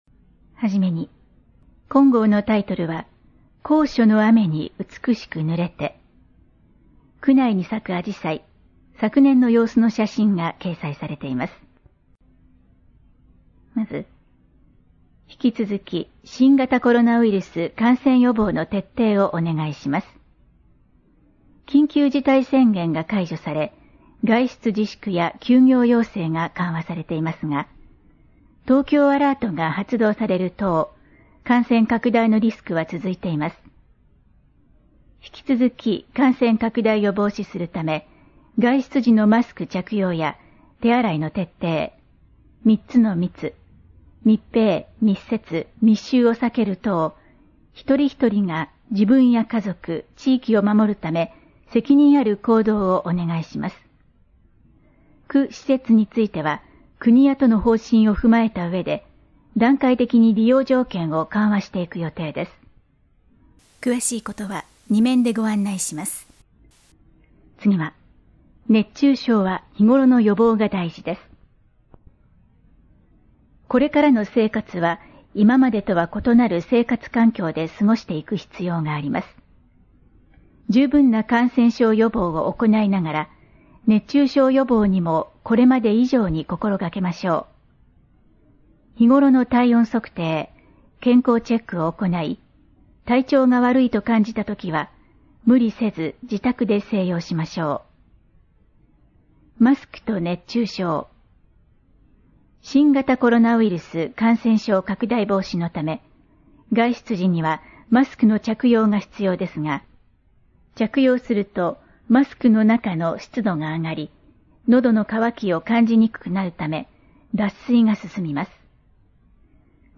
トップページ > 広報・報道・広聴 > 声のあらかわ区報 > 2020年 > 6月 > 2020年6月11日号